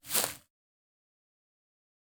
footsteps-single-outdoors-002-03.ogg